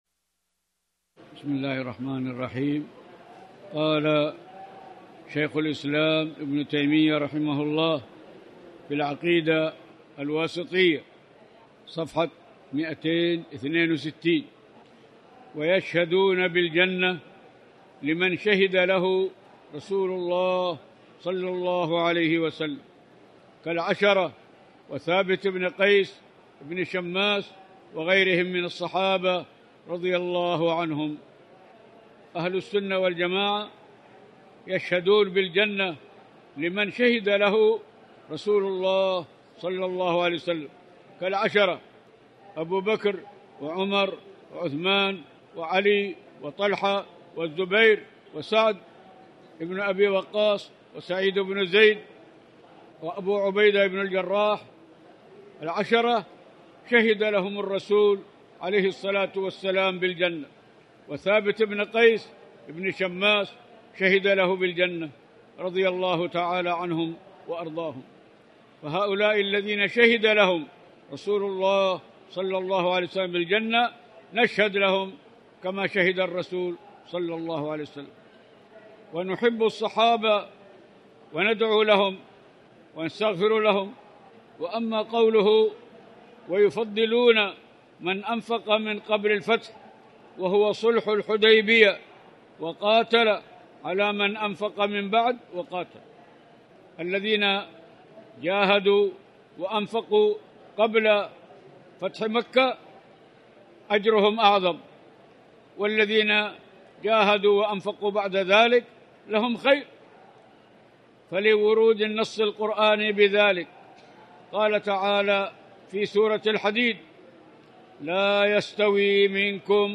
تاريخ النشر ٢١ ذو القعدة ١٤٣٨ هـ المكان: المسجد الحرام الشيخ